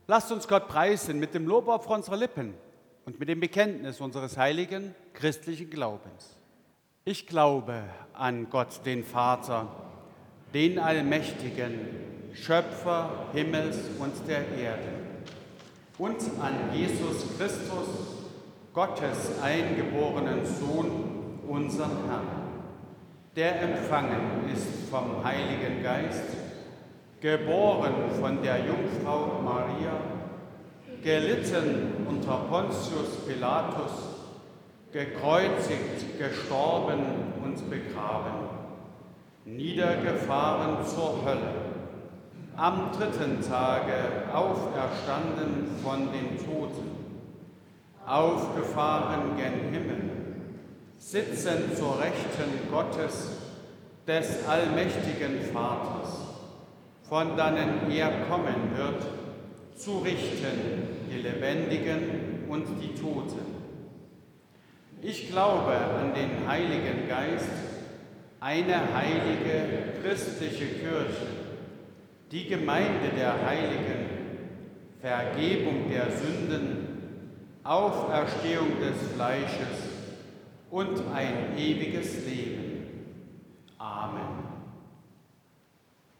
Audiomitschnitt unseres Gottesdienstes vom Vorletzten Sonntag im Kirchenjahr 2024